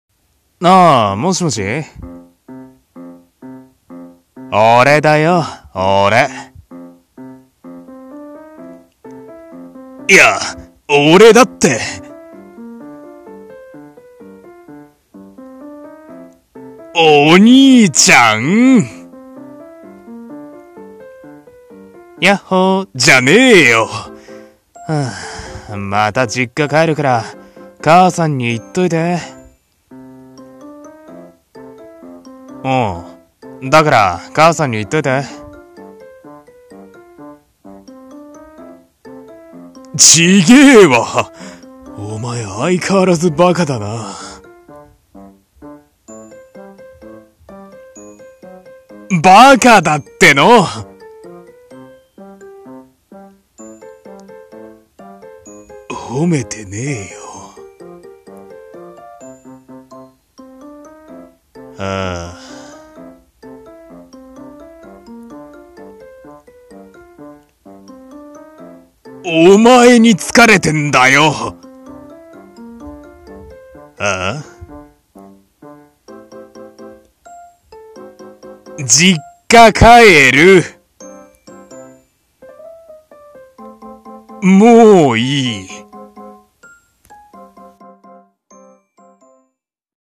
声劇台本 「妹と兄の日常電話」